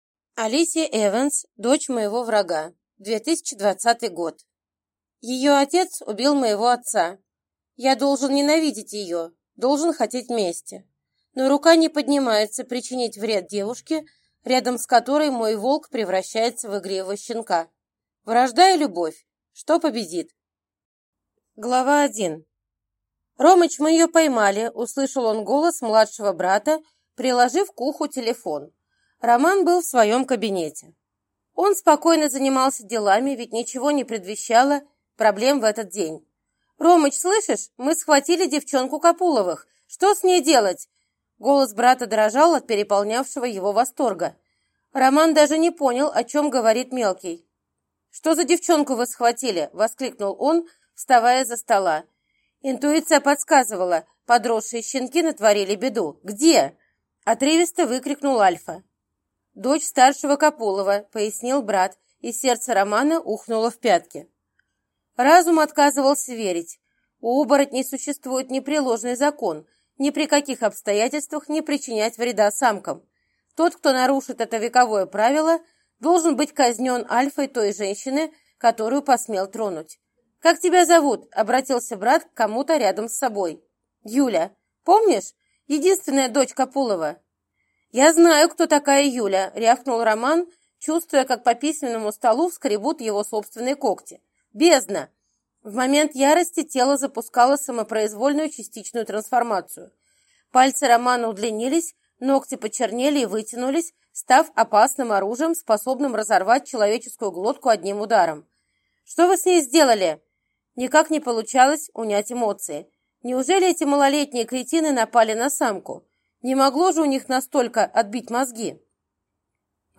Aудиокнига Дочь моего врага